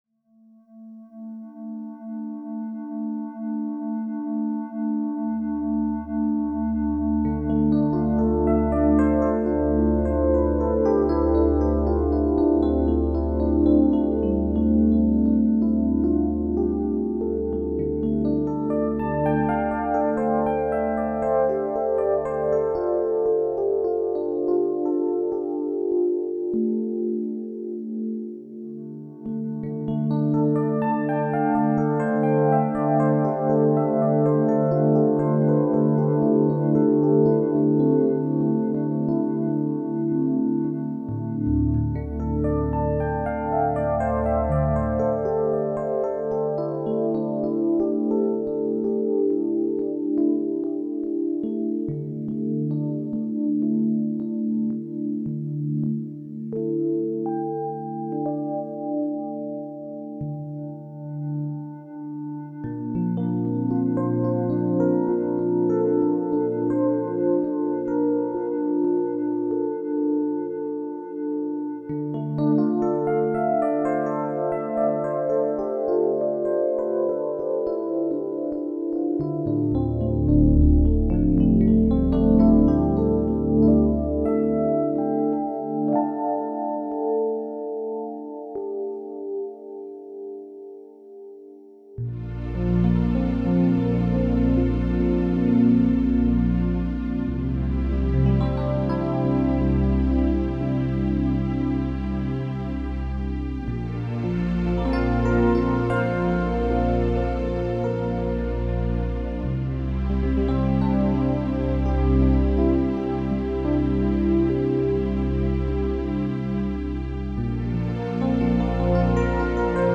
transformational healing music